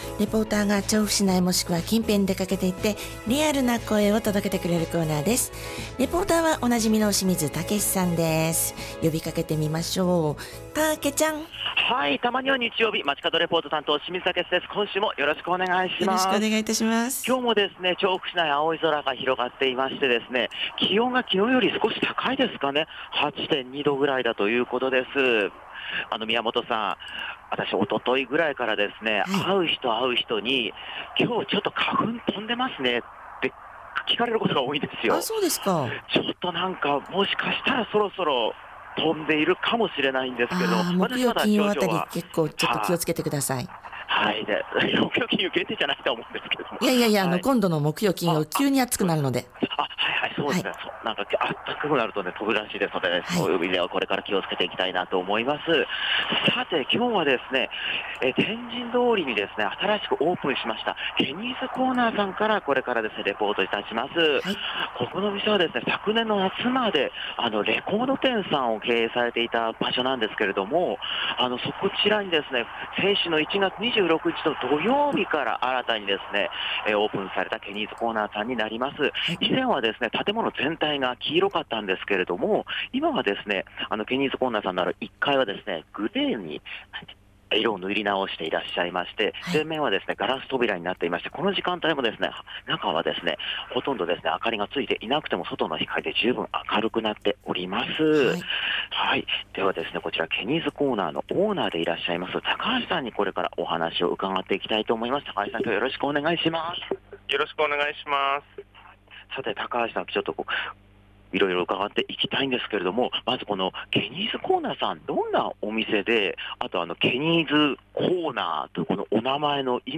風の無い澄み切った青空の下からお届けした、本日の街角レポートは 先週1月２６日(土)に天神通りにオープンしたKENNY’S CORNERさんからレポートでした。